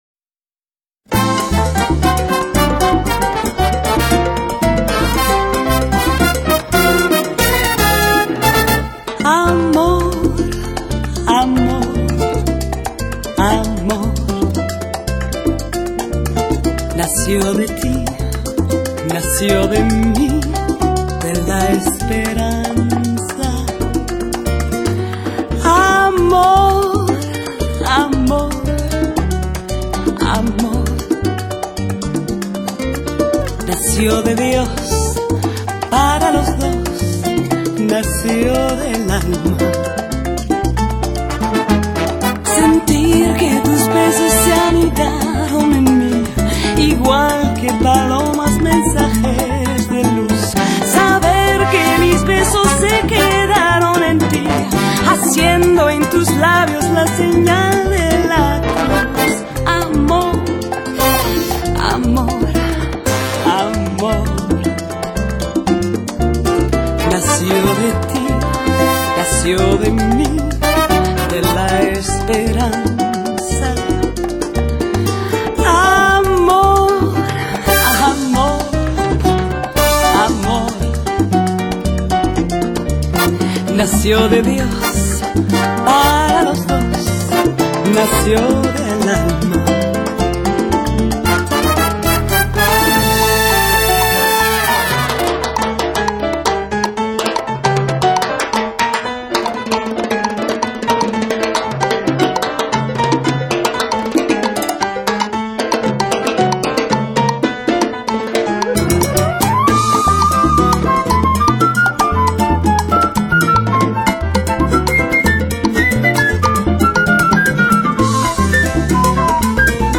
拉丁风情的曼妙JAZZ……
平易近人的旋律搭配上她温暖轻松的唱腔，让歌迷们感到温暖贴心和舒适。
火热而纯正的伦巴、恰恰、莎莎、波列罗节奏